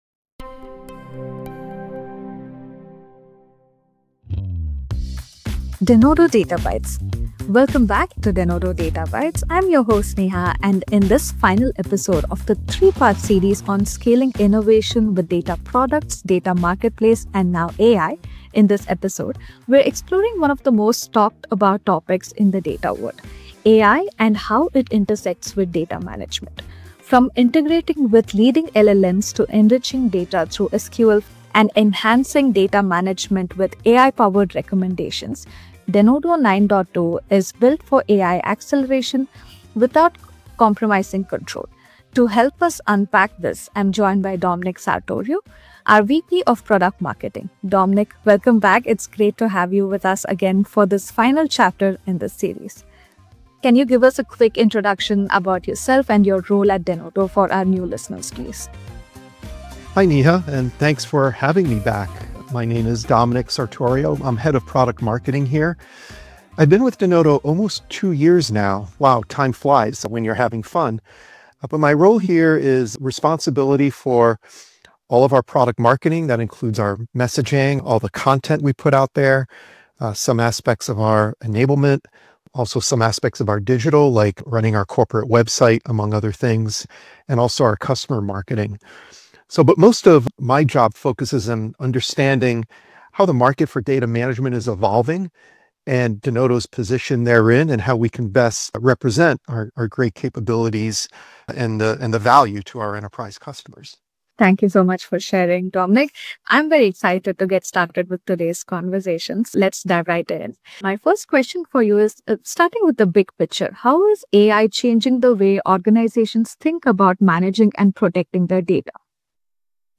A discussion that breaks down the real-world challenges of AI-driven transformation — and how organizations can stay agile, secure, and compliant while working with structured and unstructured data.